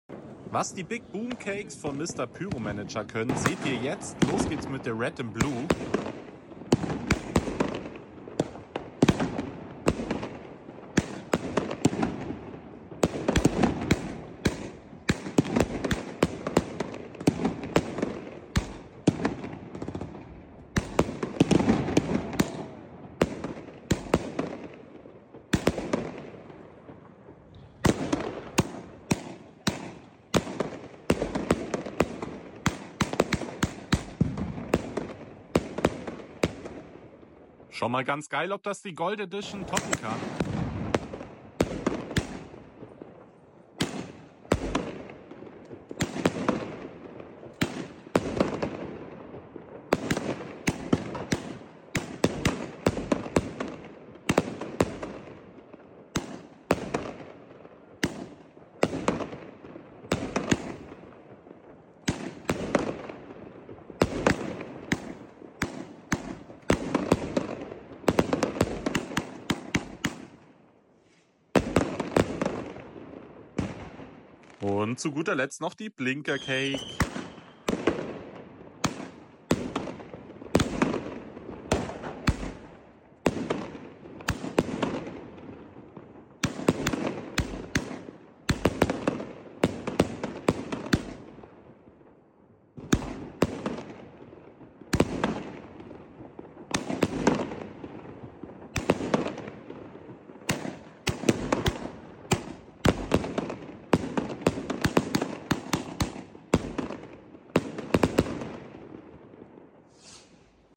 MR PYROMANAGER BIG BOOM CAKES Sound Effects Free Download